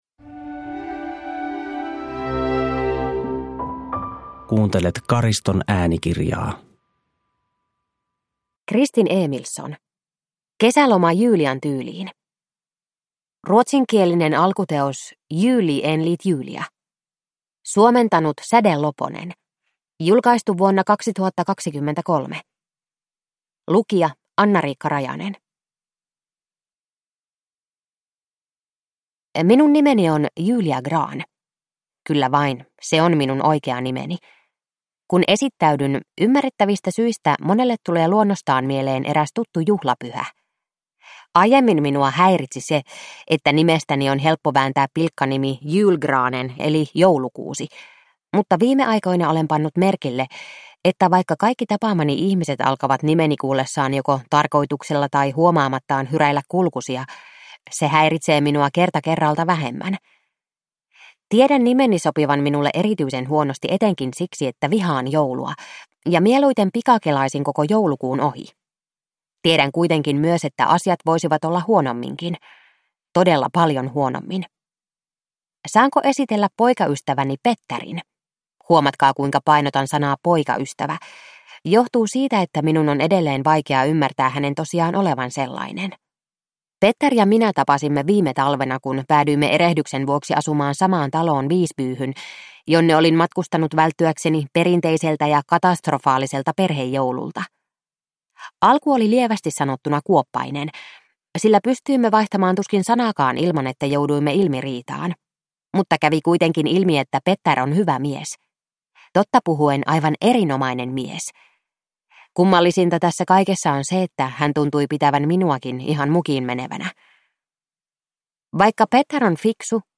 Kesäloma Julian tyyliin – Ljudbok – Laddas ner